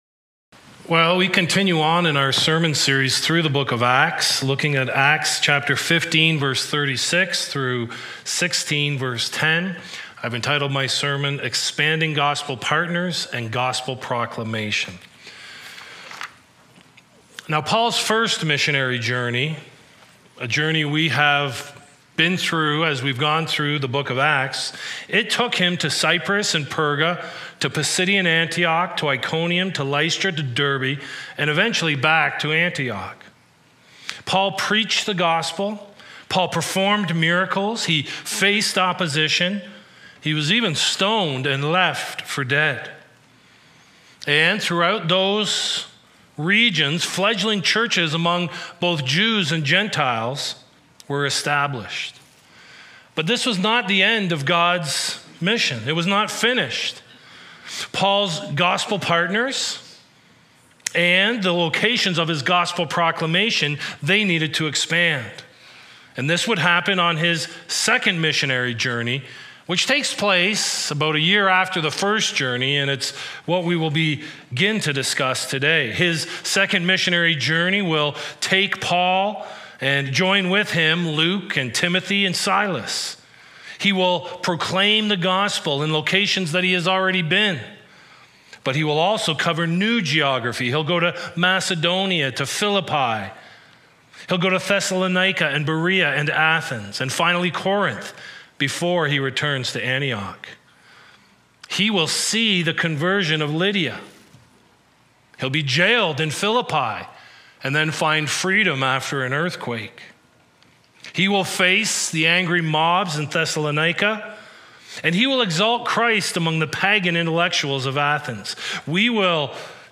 A Podcast of West London Alliance Church featuring the latest Sunday morning sermon.